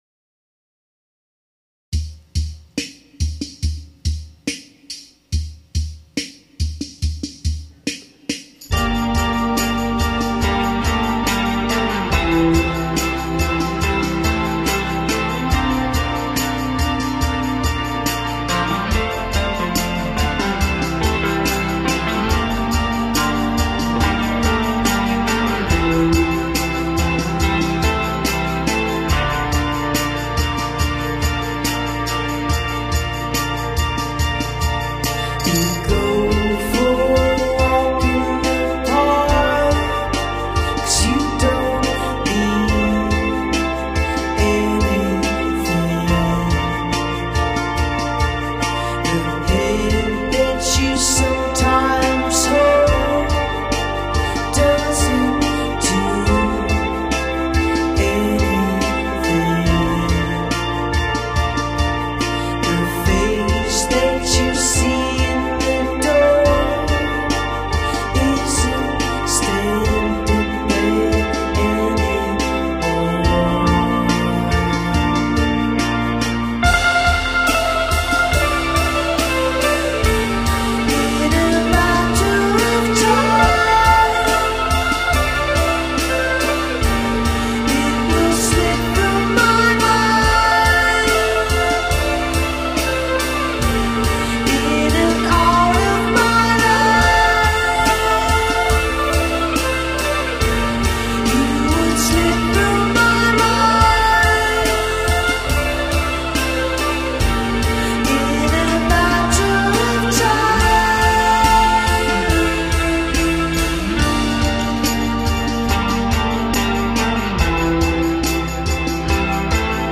melodic songcraft and the organ work